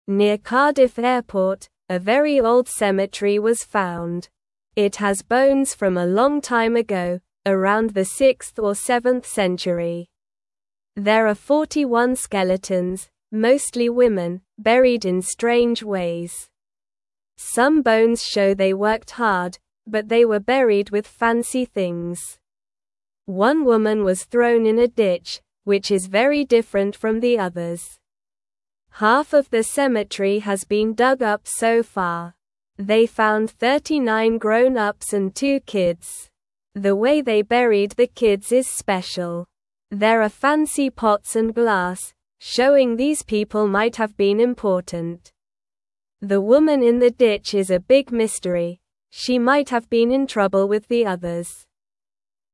Slow
English-Newsroom-Beginner-SLOW-Reading-Old-Cemetery-Found-Near-Cardiff-Airport.mp3